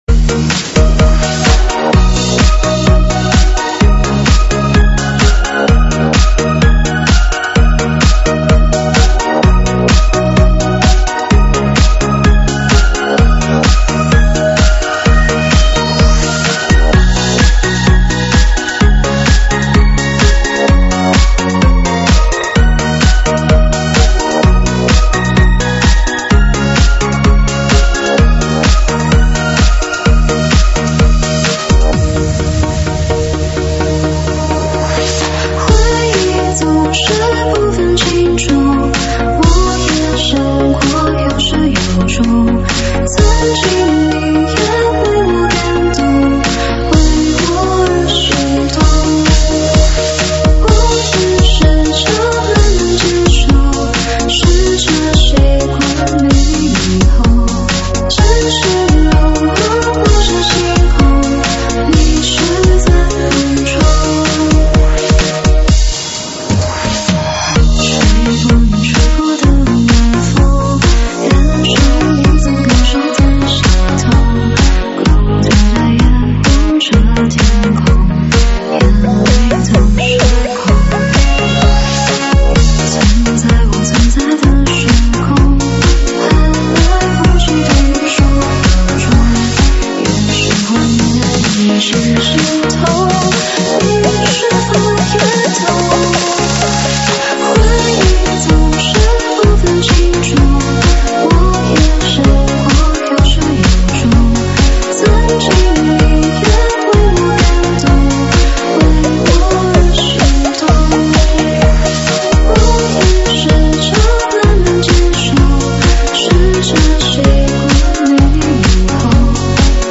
ProgHouse